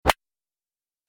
دانلود آهنگ تصادف 38 از افکت صوتی حمل و نقل
دانلود صدای تصادف 38 از ساعد نیوز با لینک مستقیم و کیفیت بالا